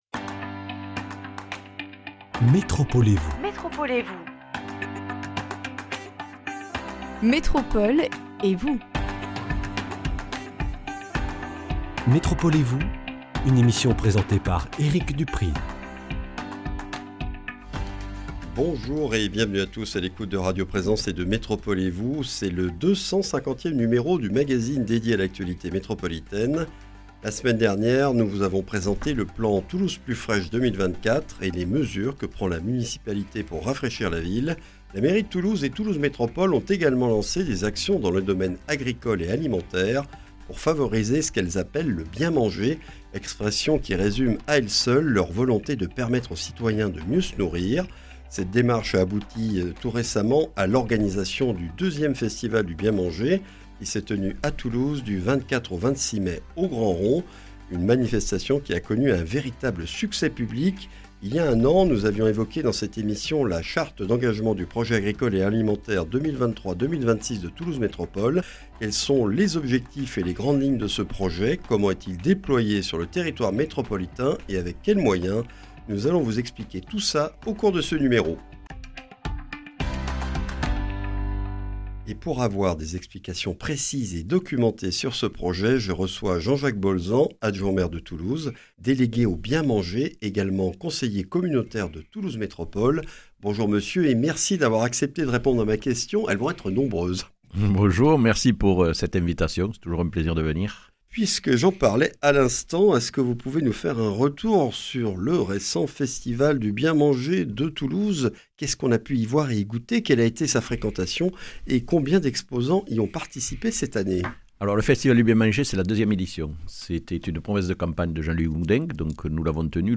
L’invité de cette émission est Jean-Jacques Bolzan, adjoint au maire de Toulouse délégué au "Bien manger", conseiller communautaire de Toulouse Métropole, avec qui nous revenons sur le récent succès du 2e Festival du Bien Manger de Toulouse (26 000 visiteurs) avant de faire le point sur le Projet agricole et alimentaire 2023-2026 de Toulouse Métropole, ses actions et ses objectifs.